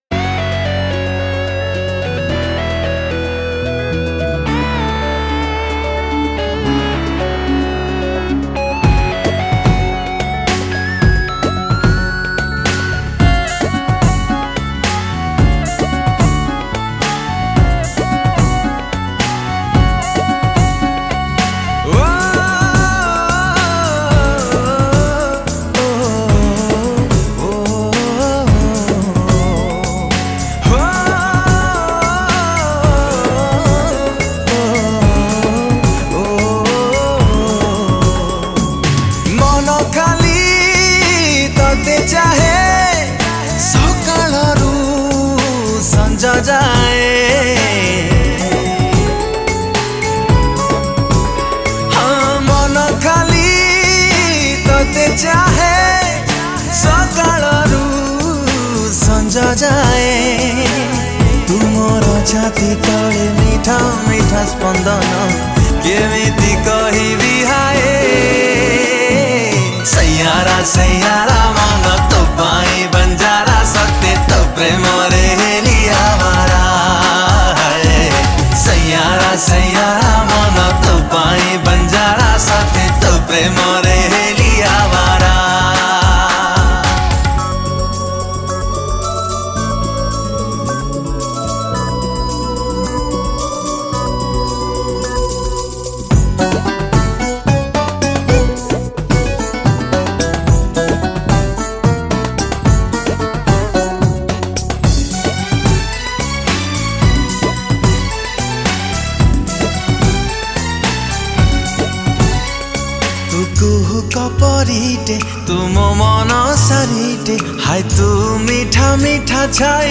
Odia Sad Romantic Songs